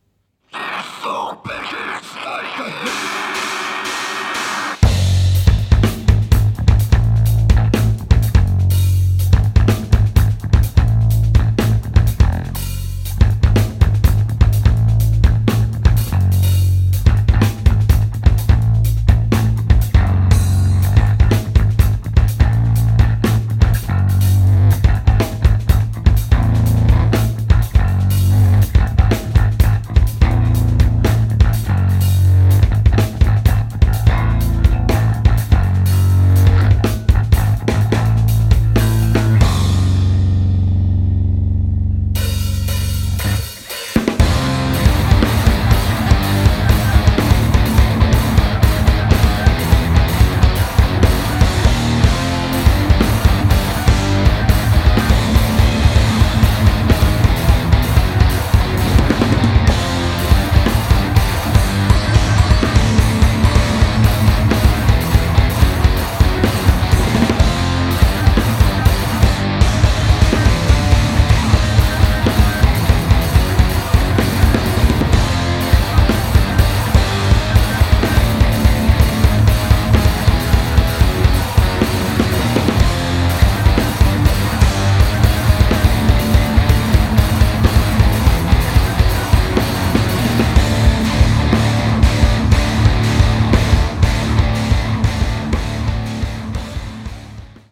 alto saxophone, electronics
electric bass, effects
drums
Recorded live at Club Soto in April 2018
'avant-jazz-metal'
Thus, here it is: a live recording of the band’s repertoire.